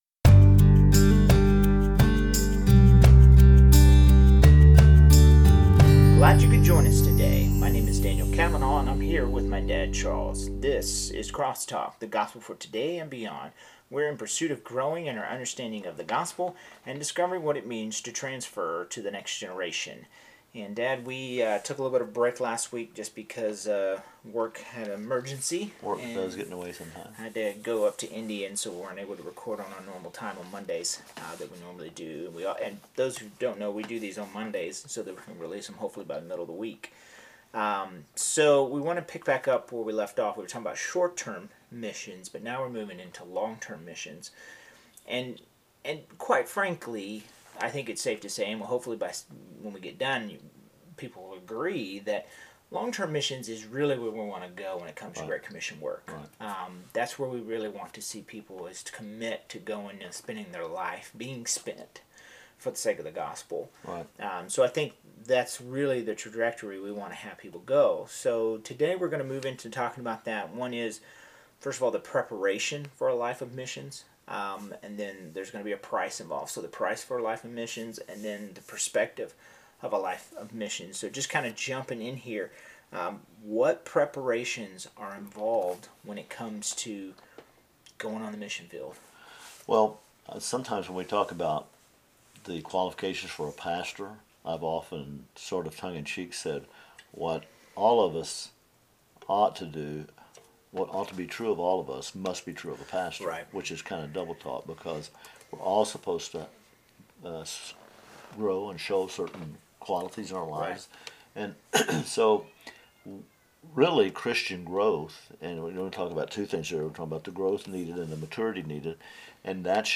EP 6.209 | Missions: A Pastoral Interview - Vision4Living